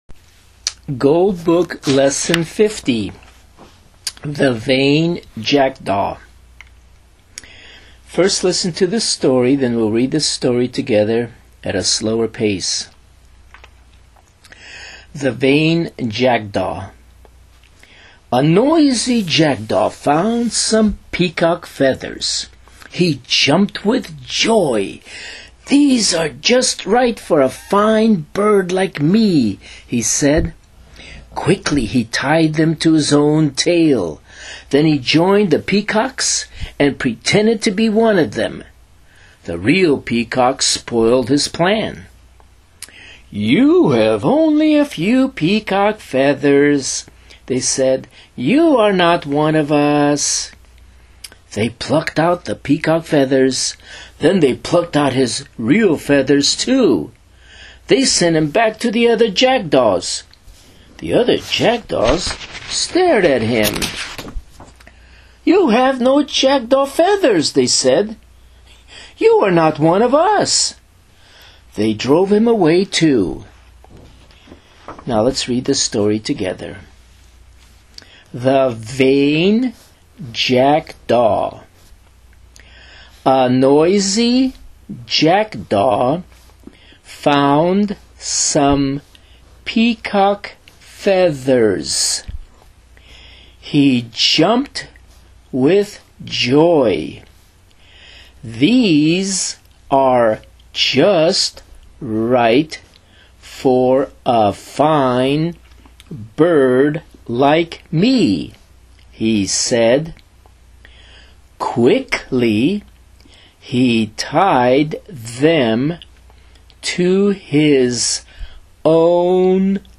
LISTEN & READ with the TEACHER